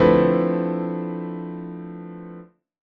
To get started, lets begin in a familar key, the key of D. The foundation of this D-9 (13) will be a D-7 chord.
In D, the E is the 9th of the chord and the B is the 13th.
D-9-13-1.wav